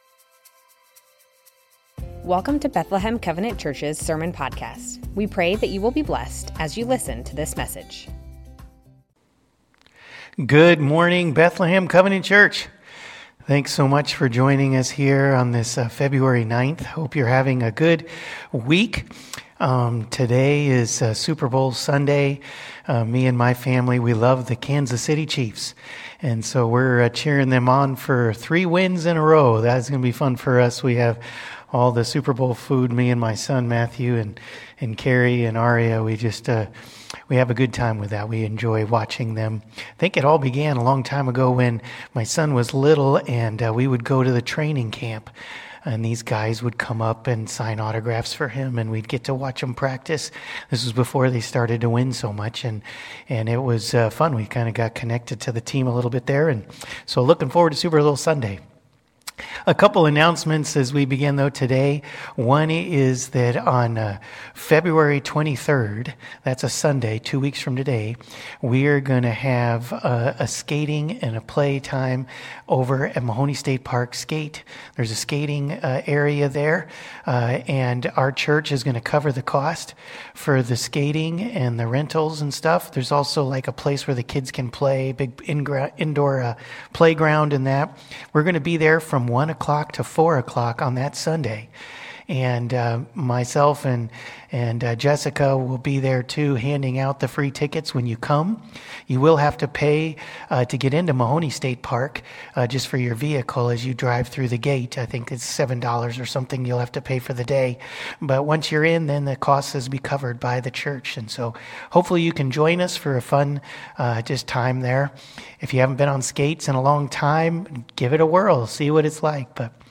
Bethlehem Covenant Church Sermons Matthew 5:13-16 - Salt & Light Feb 09 2025 | 00:33:04 1x 00:00 / 00:33:04 Subscribe Share Spotify RSS Feed Share Link Embed